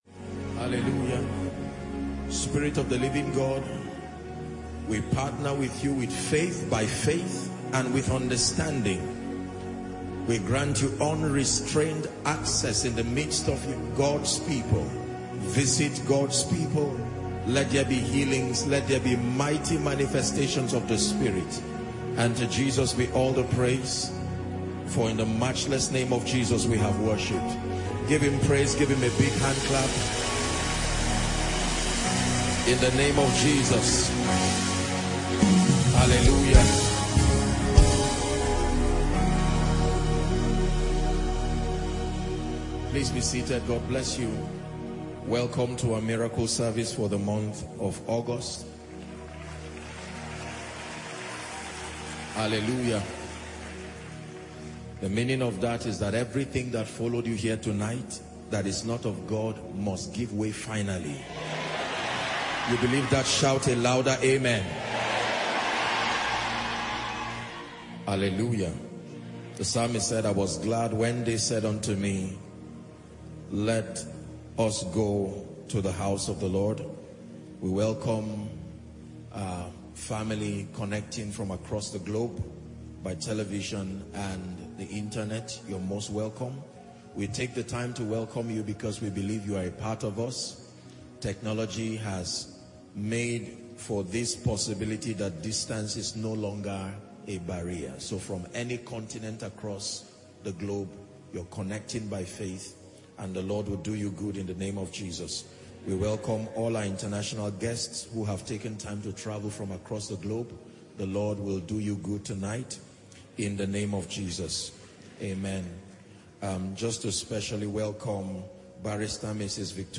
Koinonia Miracle service is a monthly programme organized by the Eternity Network International (ENI).